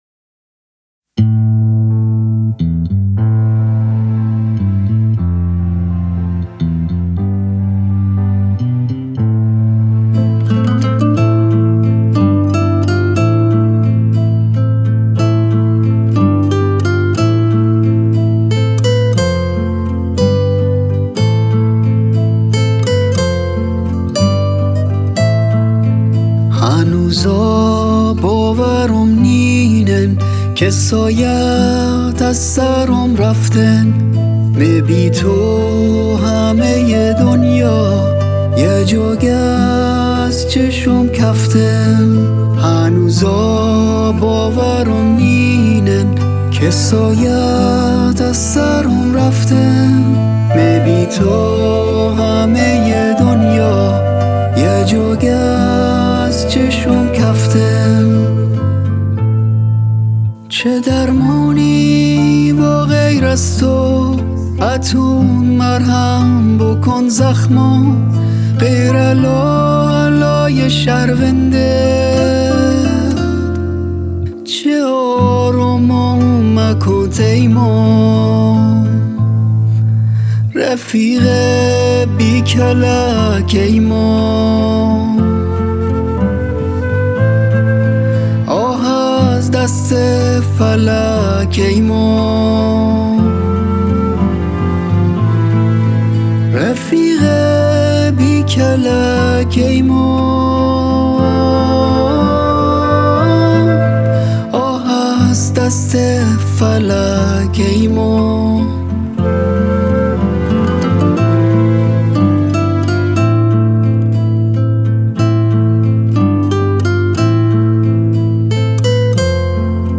گیتار باس
فلوت-ویولن سل-گیتار